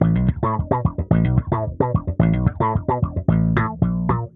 Index of /musicradar/dusty-funk-samples/Bass/110bpm